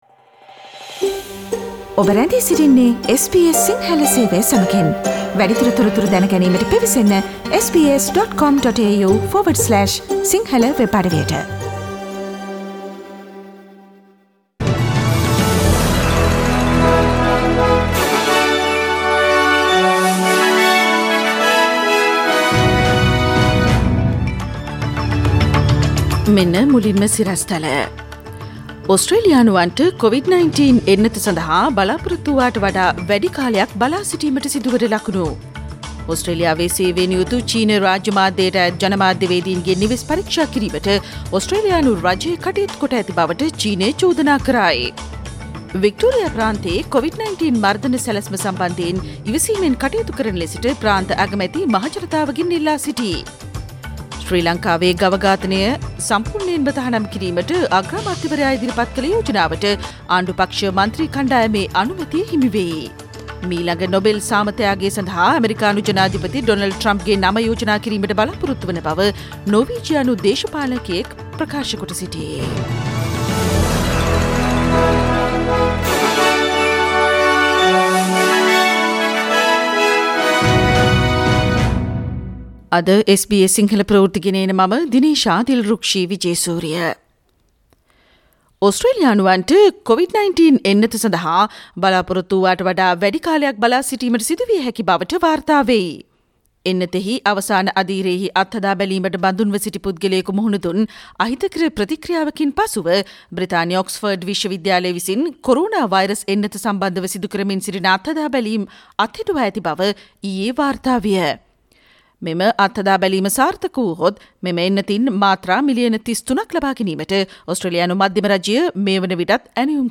Today’s news bulletin of SBS Sinhala radio – Thursday 10 September 2020
Daily News bulletin of SBS Sinhala Service: Thursday 10 September 2020